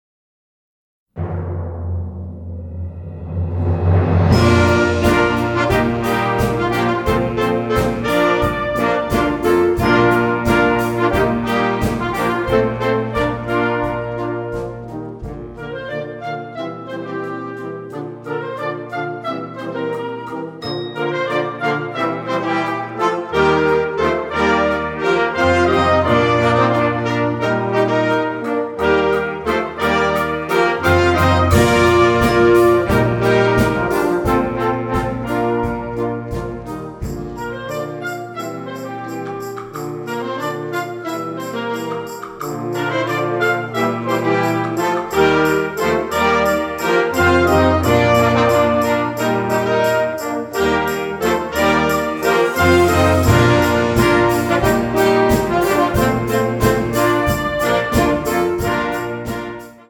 Gattung: variables Ensemble
Besetzung: Blasorchester